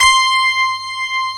Index of /90_sSampleCDs/USB Soundscan vol.09 - Keyboards Old School [AKAI] 1CD/Partition A/17-FM ELP 5